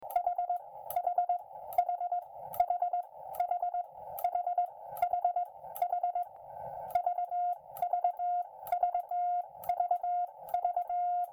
時定数がちょっと短い気もするが結果オーライ、単点、頂点とも綺麗な音
こんな音です（チャタリング対策後MP3)